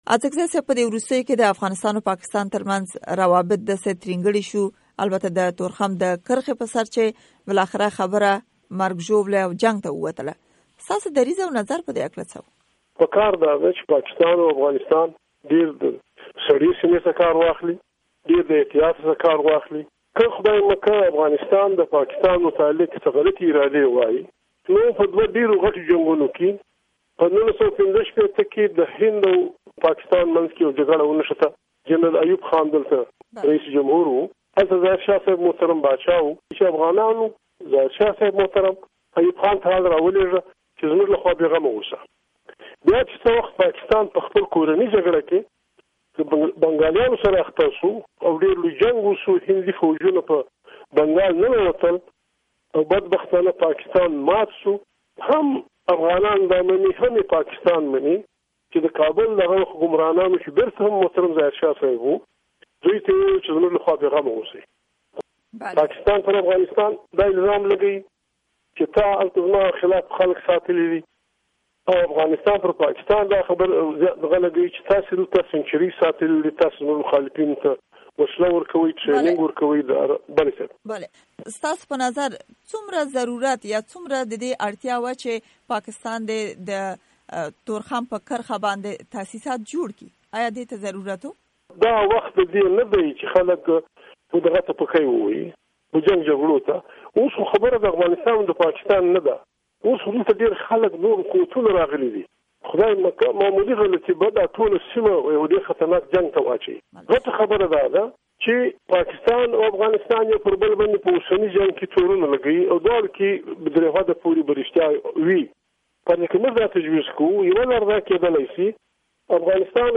ښاغلي اڅکزي اشناراډيو ته په مرکه کې وويل : دواړه غاړې دې له...